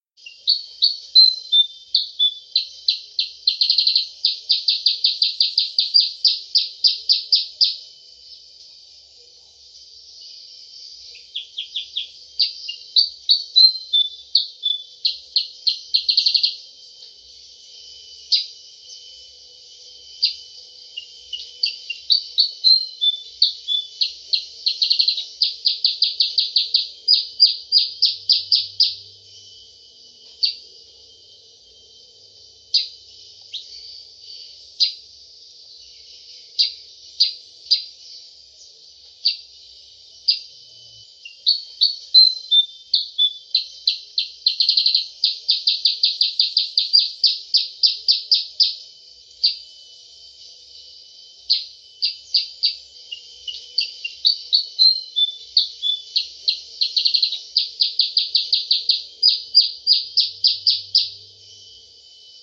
Download Suara Burung Kutilang Pikat Mp3 Durasi Panjang
Suara Kicau Burung Kutilang Sutra | DOWNLOAD